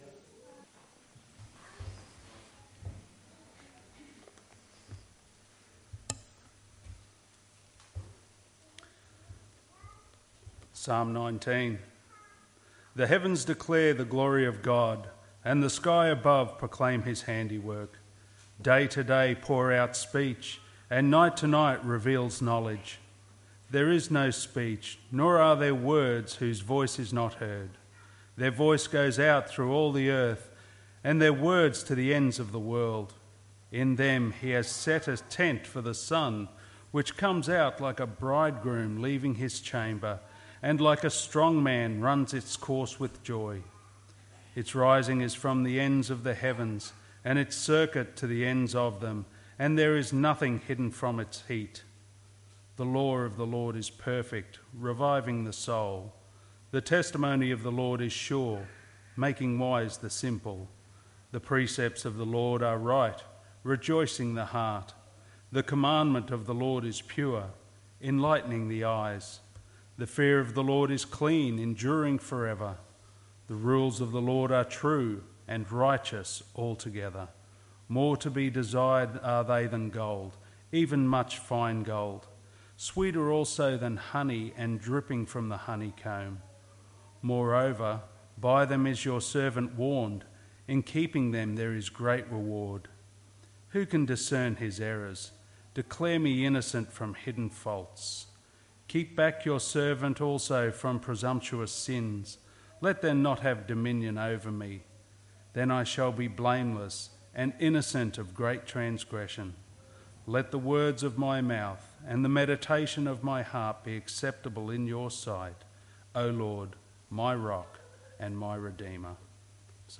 May 07, 2023 Necessity, Unity and Efficacy of God’s Word MP3 SUBSCRIBE on iTunes(Podcast) Notes Sermons in this Series 07th May 2023 Evening Service Psalm 19 Romans 10:1-19 1.Unity of Scripture Contradictions?